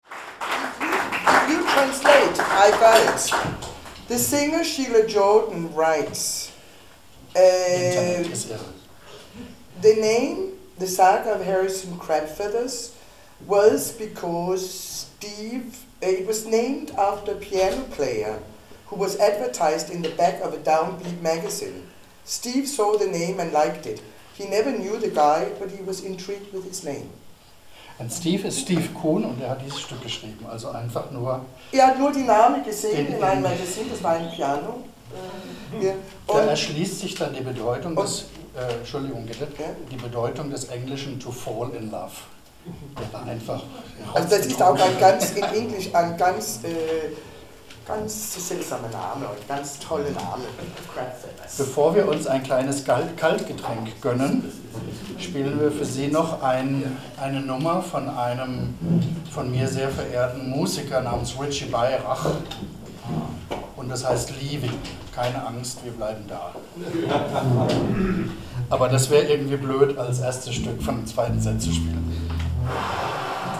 12 12 Ansage [01:14]